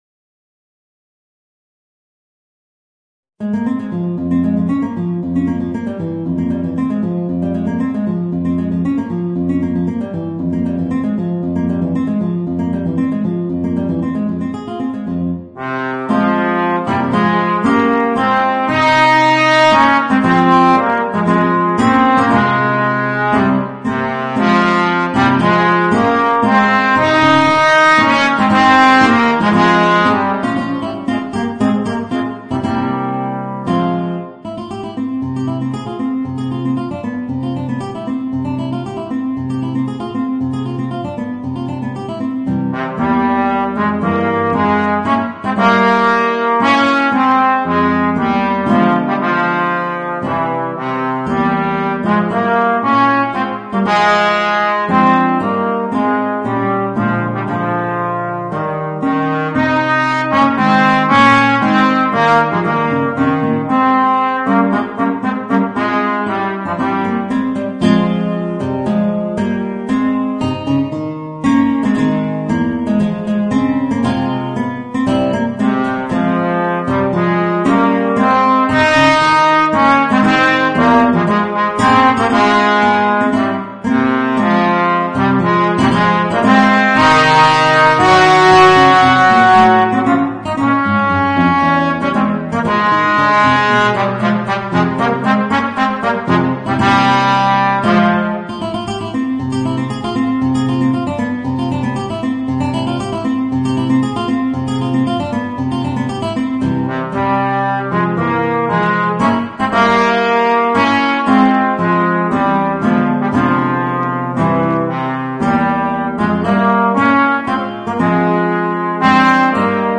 Voicing: Guitar and Trombone